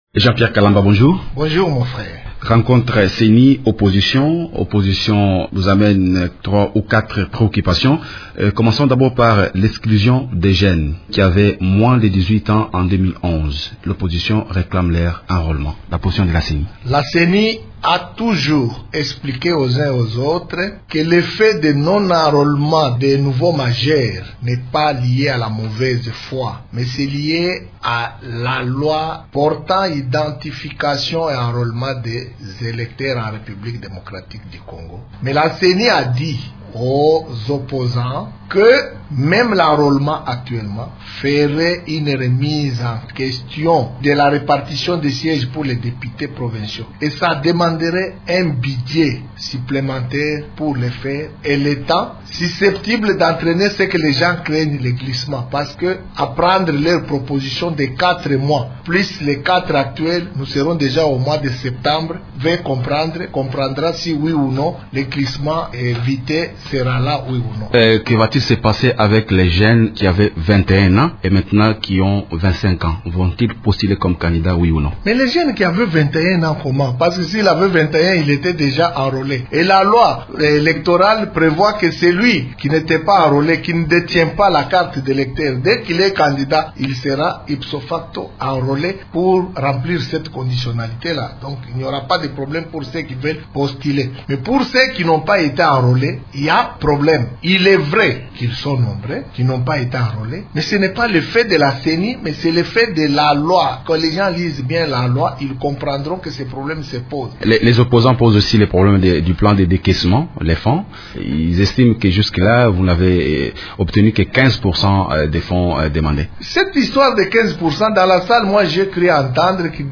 Invité de Radio Okapi ce matin, le rapporteur de la Ceni, Jean-Pierre Kalamba précise que si cela n’est pas fait, ce n’est pas « une mauvaise foi. »